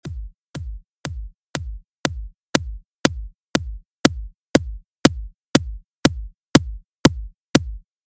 If you slowly creep up the attack control you will hear the sound start to ‘click’ – when the click becomes fairly pronounced you know that the transient ‘attack’ on the drum is coming through, and that your attack setting is correct: